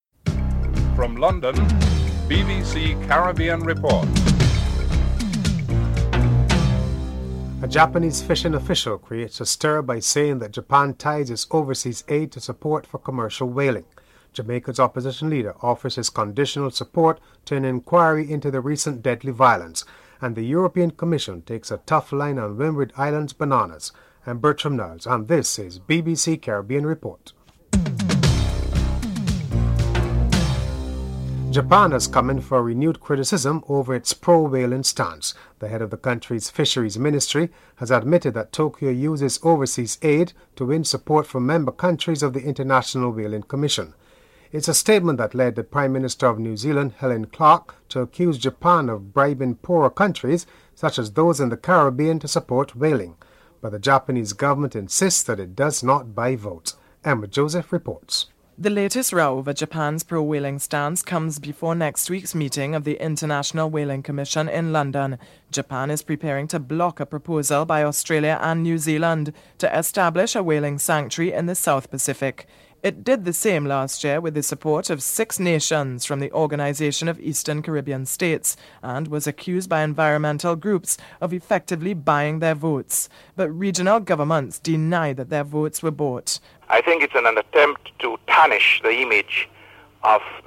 The British Broadcasting Corporation
1. Headlines (00:00-00:30)